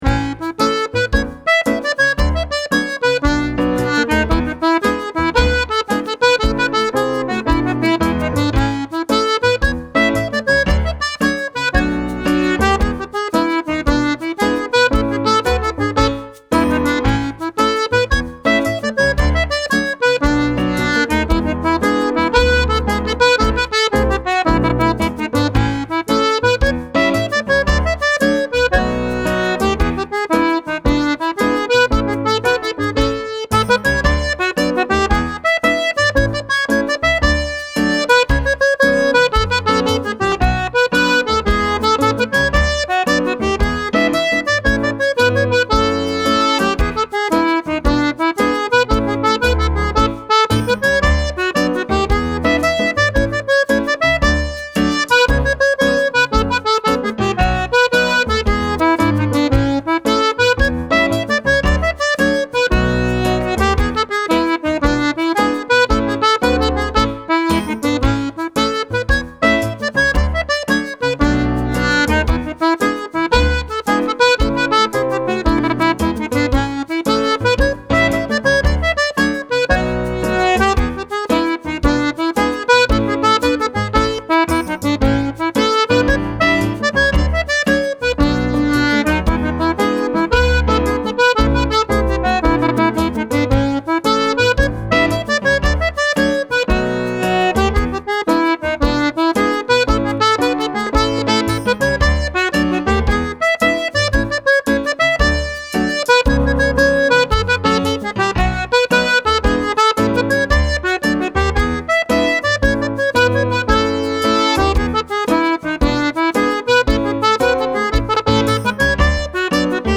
Hornpipes